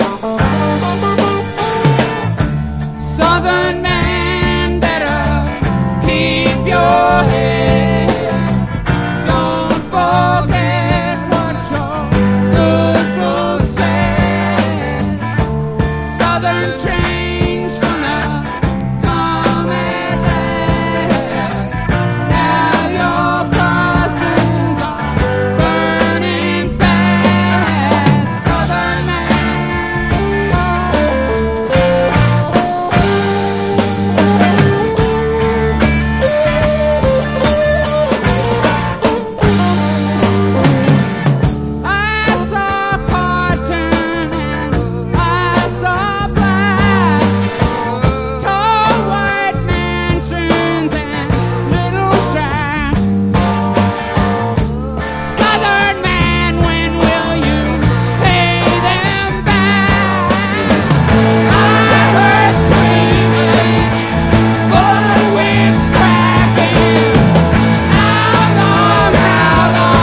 ROCK / 70'S / O.S.T.